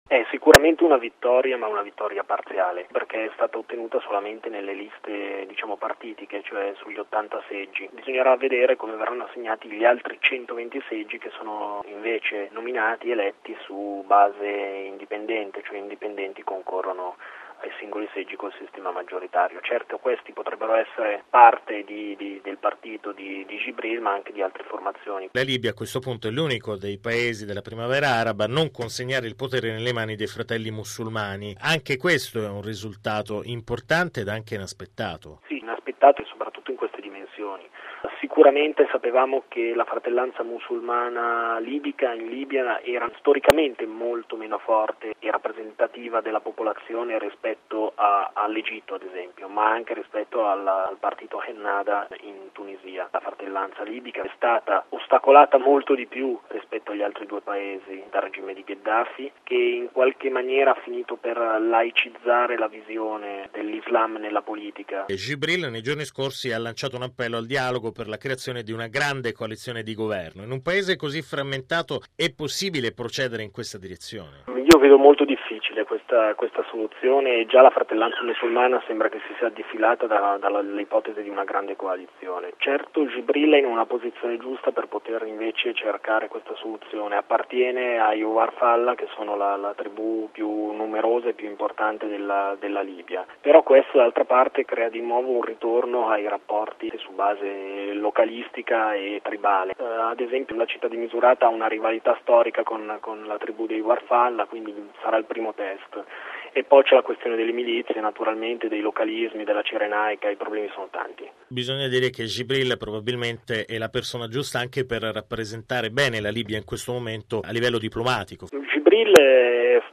Radiogiornale del 18/07/2012 - Radio Vaticana